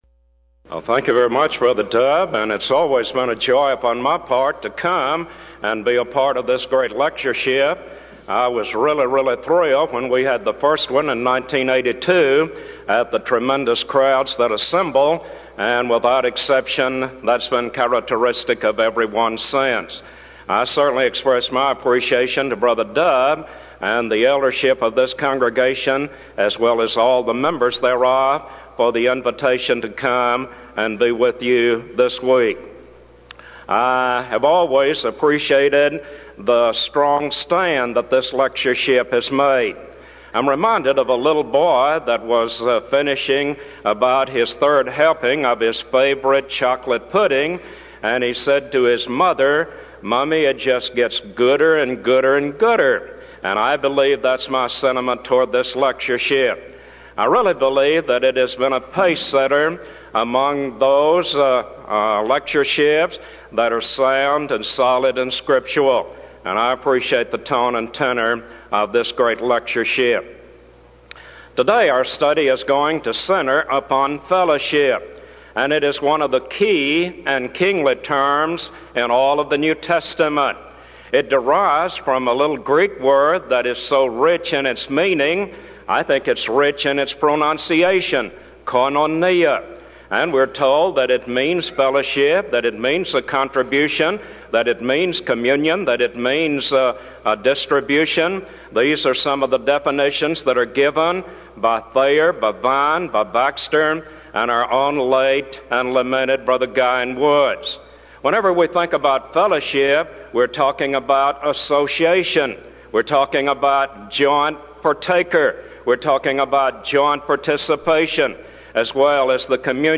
Title: DISCUSSION FORUM: Where And When Shall We Draw The Line Of Fellowship?
Event: 1994 Denton Lectures